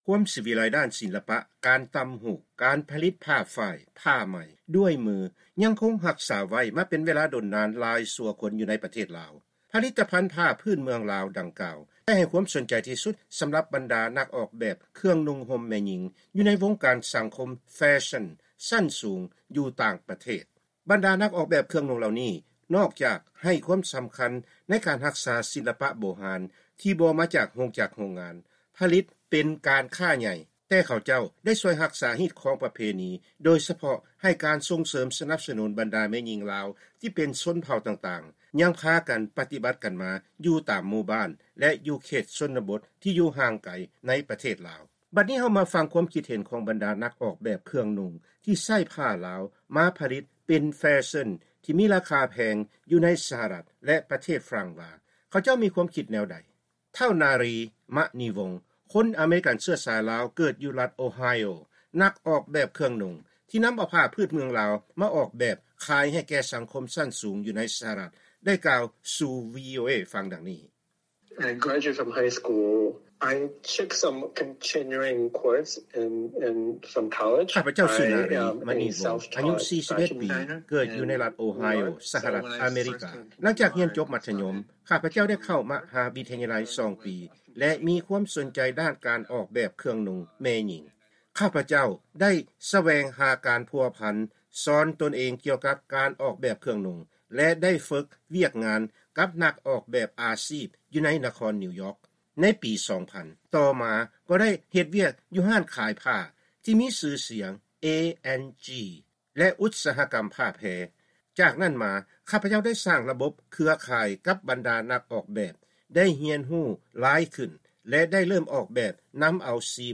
ໃນລາຍການຊີວິດຊາວລາວ ຂອງວີໂອເອ ສຳລັບແລງວັນພະຫັດມື້ນີ້ ເຮົາຈະນຳເອົາການໂອ້ລົມ ກັບບັນດານັກອອກແບບເຄື່ອງນຸ່ງແມ່ຍິງ ສາມທ່ານ